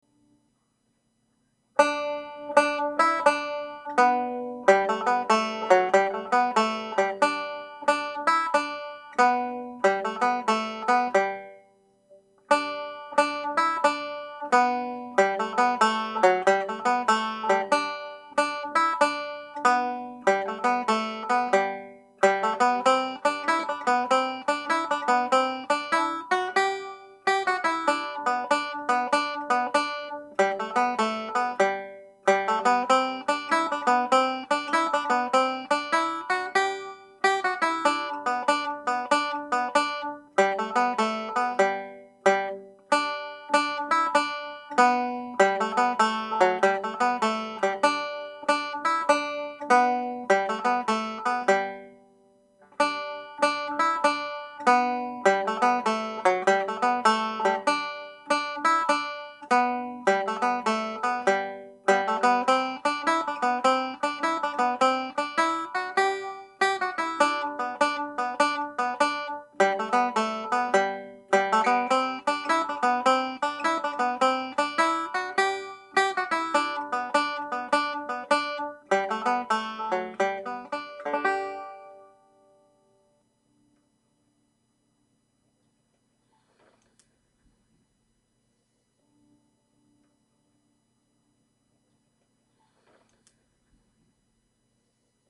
(G Major)
Tune played at normal speed